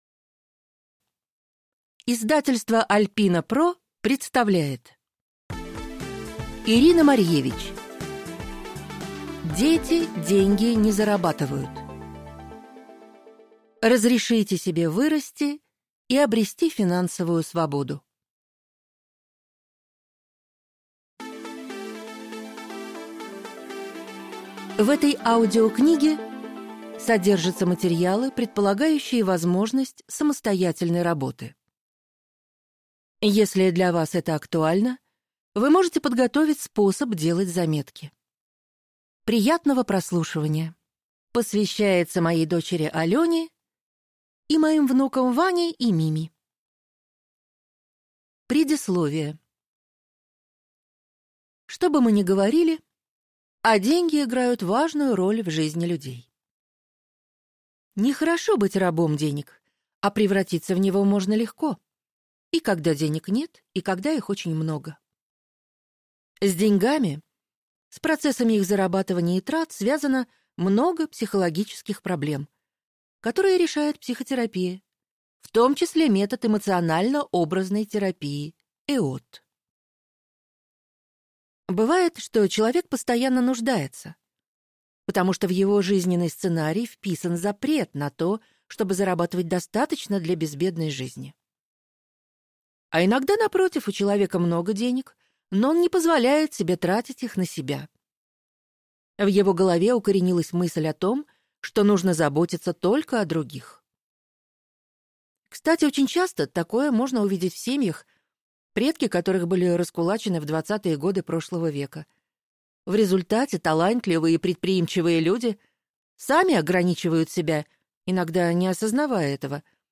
Аудиокнига Дети деньги не зарабатывают. Разрешите себе вырасти и обрести финансовую свободу | Библиотека аудиокниг